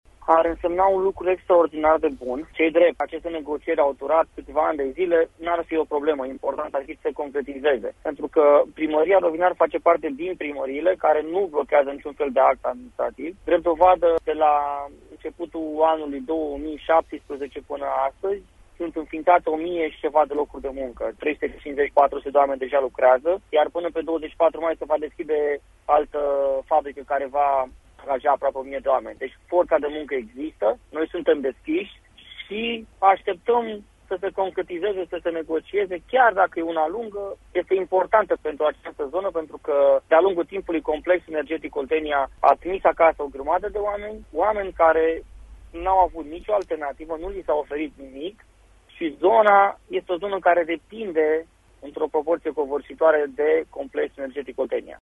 Astăzi, la „Pulsul zilei”, primarul din Rovinari, Robert Filip, a explicat ce ar însemna această investiție pentru comunitatea locală:
5-februarie-Robert-Filip-primar-Rovinari.mp3